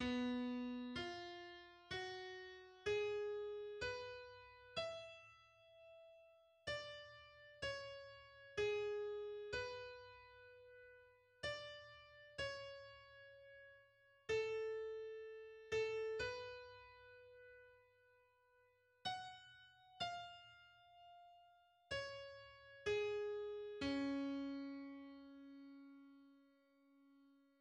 Andante tranquillo
Second theme (mm. 437–445)